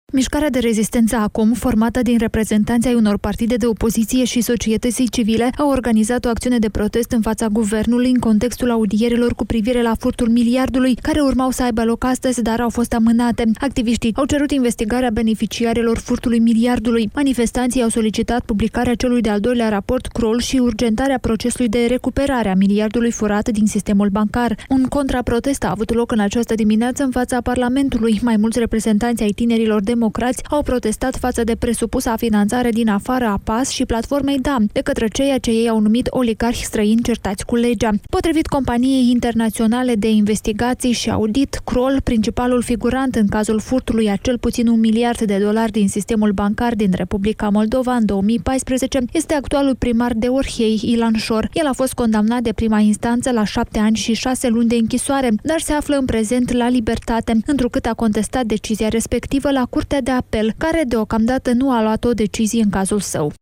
În Republica Moldova, zeci de membri ai Mişcării de Rezistenţă Naţională, ACUM, formată din câteva partide de opoziţie şi lideri de opinie, au ieşit astăzi în stradă şi au cerut investigarea furtului miliardului de dolari din 2014 din sistemul bancar al Republicii Moldova. În acelaşi timp, reprezentanţi ai aripei tinere a Partidului Democrat au protestat faţă de o presupusă finanţare din exterior a principalelor partide de opoziţie din R.Moldova. Relatează de la Radio Chişinău